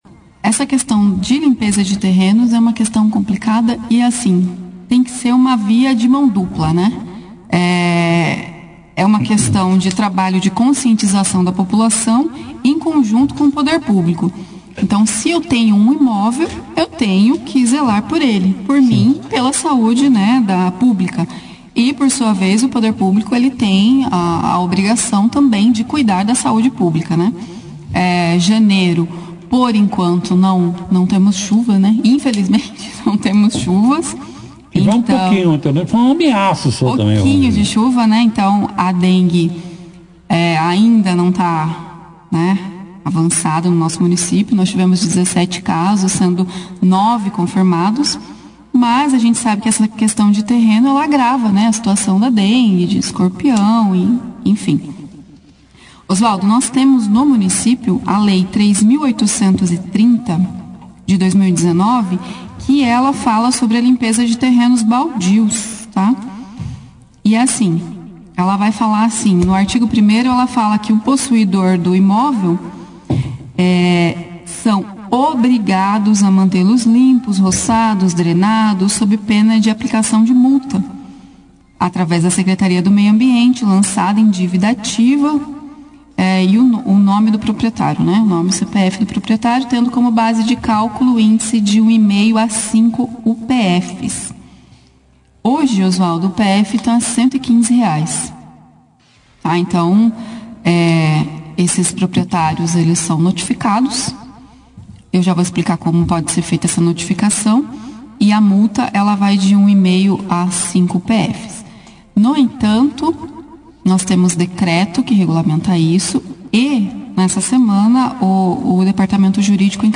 Na manhã desta quarta-feira, 10 de janeiro, a Dra. Claudia Janz, Secretária de Administração de Bandeirantes, e Vinicius Luiz Castellar, Secretário do Meio Ambiente e Recursos Hídricos, participaram da 2ª edição do programa jornalístico Operação Cidade. Durante a entrevista, eles falaram da preocupante situação de terrenos baldios na cidade, caracterizados por mato alto e acumulação de resíduos sólidos.